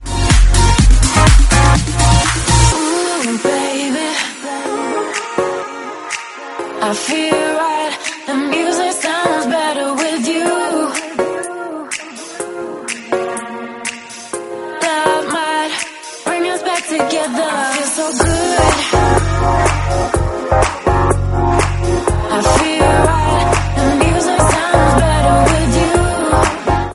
duo de DJs français
L’hymne de la French Touch
c’est clairement rejoué par les DJs